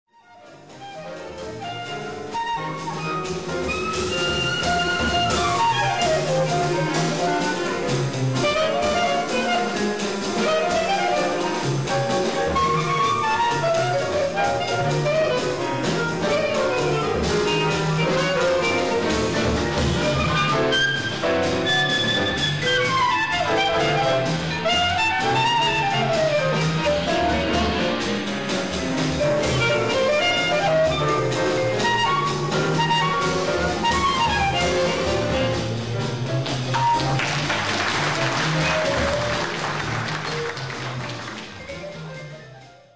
Vibrafon
Piano
Klarinette und Saxofon
Bass
Schlagzeug